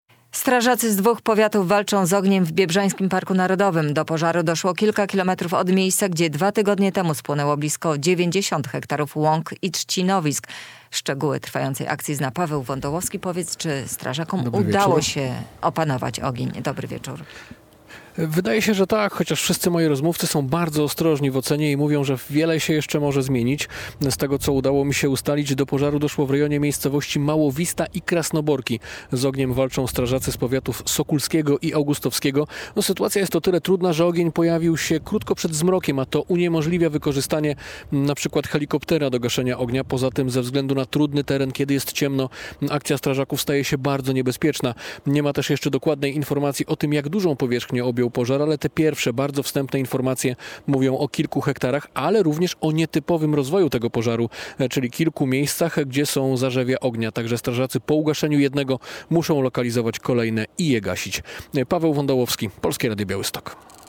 Biebrzański Park Narodowy znowu płonie - relacja LIVE